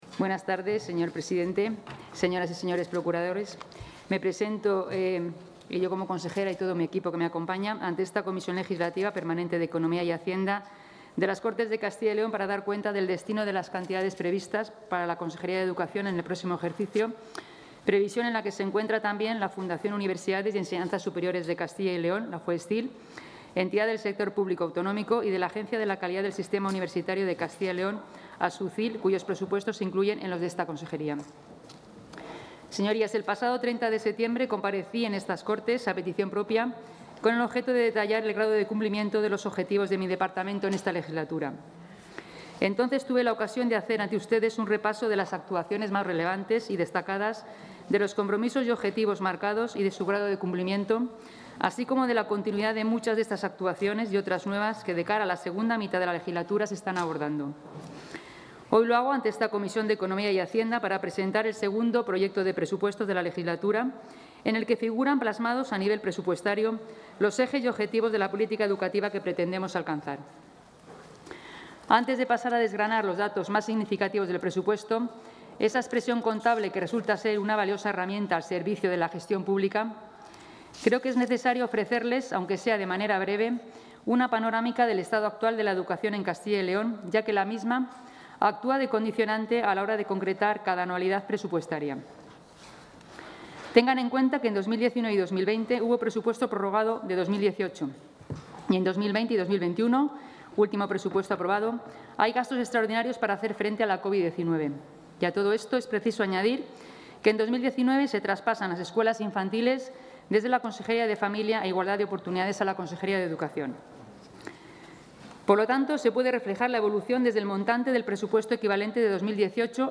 Comparecencia de la consejera de Educación.
La consejera de Educación ha comparecido hoy en la Comisión de Economía y Hacienda de las Cortes de Castilla y León para explicar las cuentas de su departamento